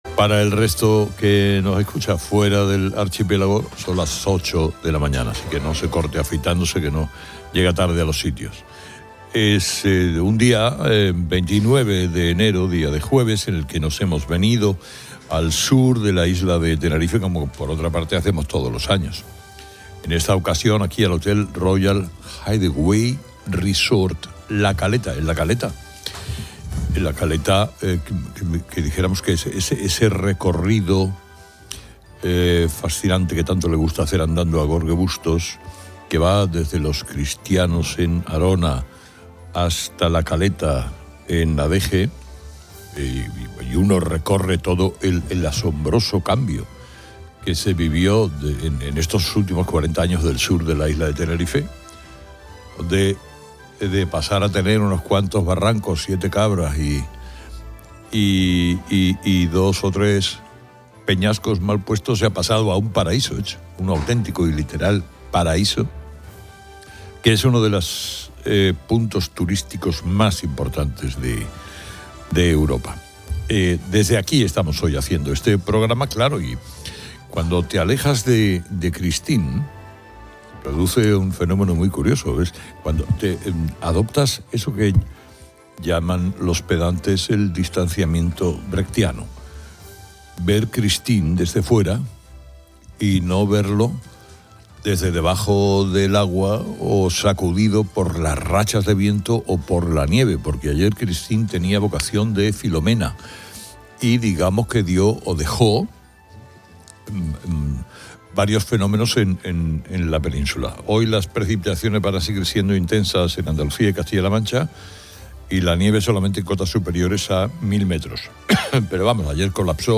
El programa, desde Tenerife, contrasta el buen tiempo local con la borrasca Christine en la Península.